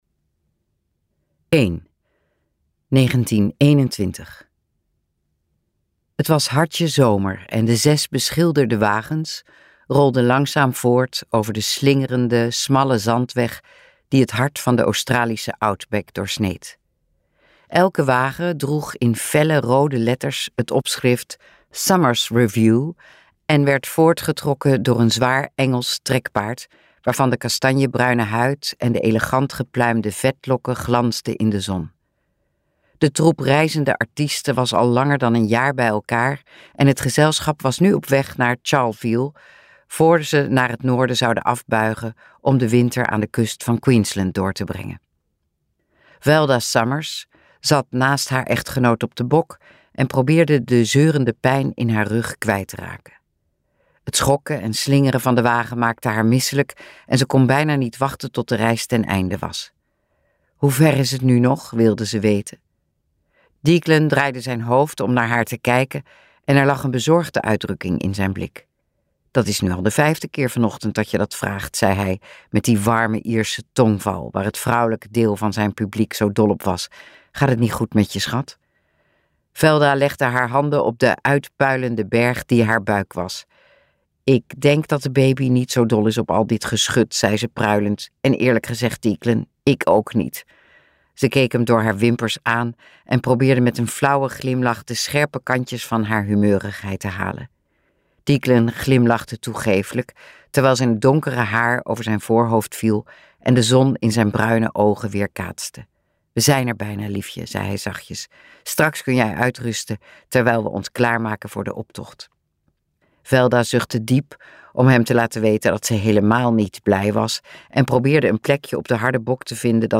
Uitgeverij De Fontein | Droomvlucht luisterboek